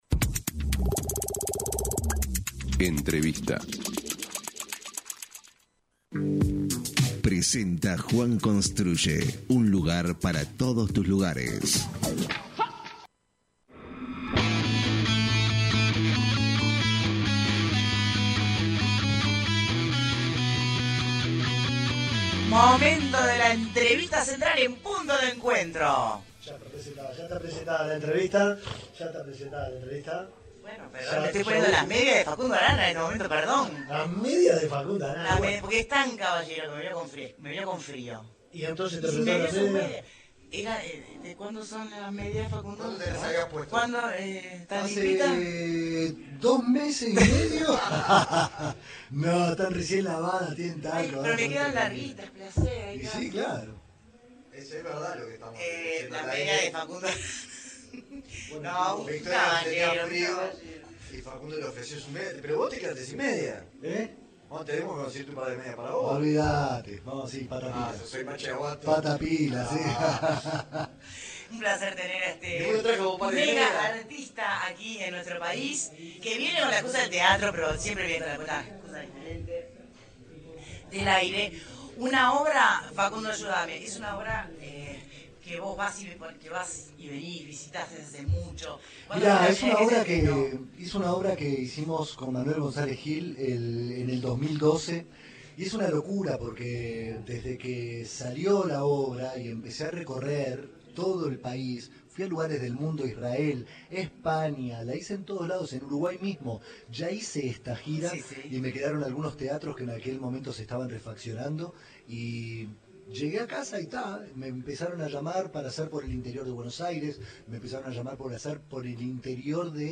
En entrevista con Punto de Encuentro, el actor argentino Facundo Arana se refirió a la grieta que hay en su país y cómo vivió en la pandemia la muerte de su padrino al que no pudo despedir, mientras en la residencia presidencial se hacían fiestas.